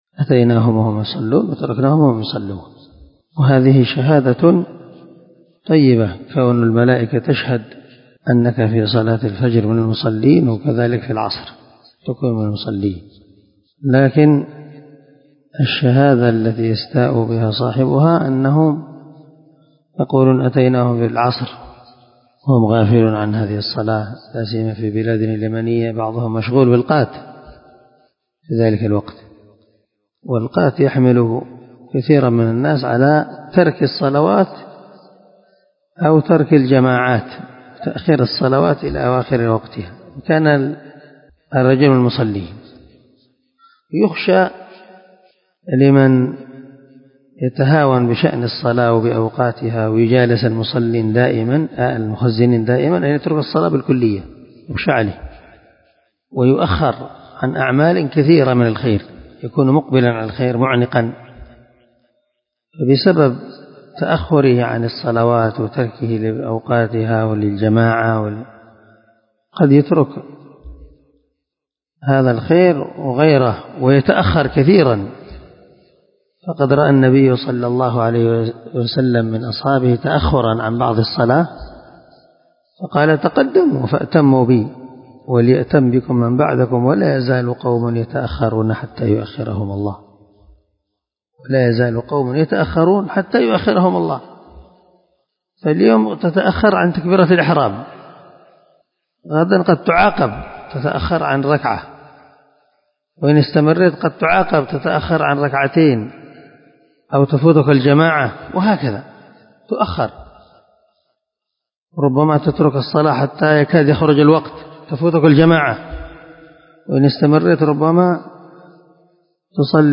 مقتطف من درس البخاري بعنوان أضرار القات وحرمته
مقتطف-من-درس-البخاري-بعنوان-أضرار-القات-وحرمته_01_01-1.mp3